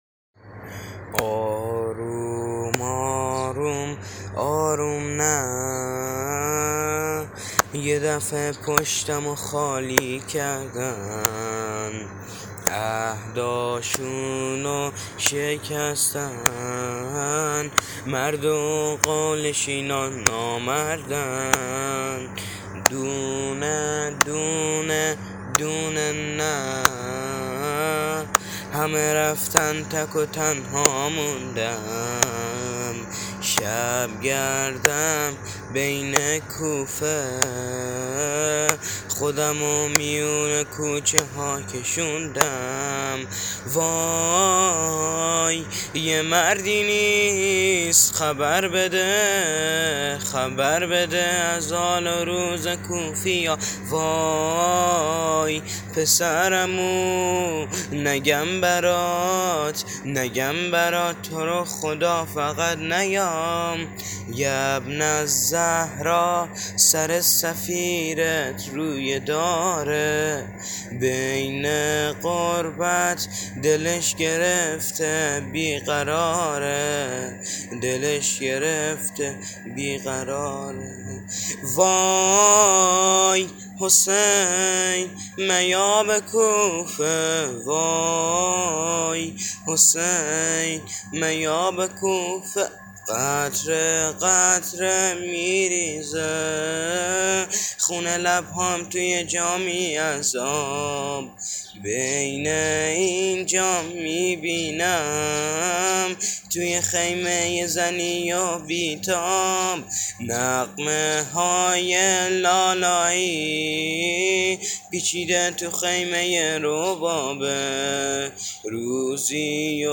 ورودی به کوفه(استودیویی